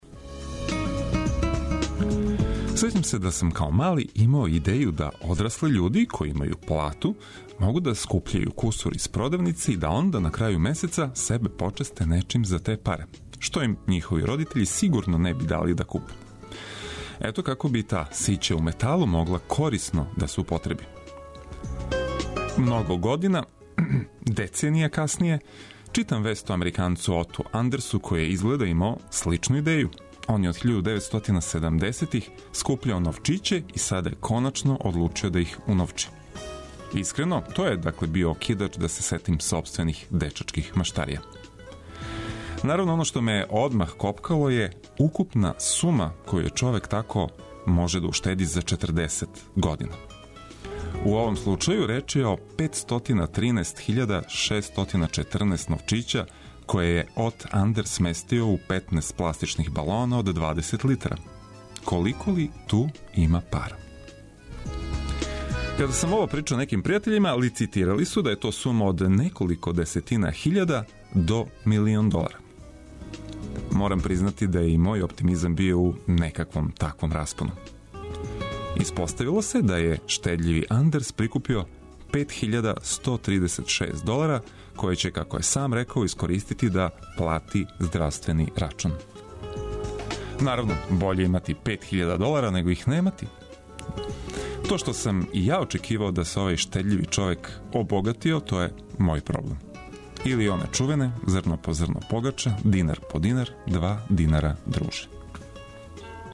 Овога јутра орасположићемо вас графитима и Кваком, а чућете и неке филмске предлоге. Уз узобичајени сет информације и прогнозе за данашњи дан, биће ту и музика за лакше напуштање топлог кревета!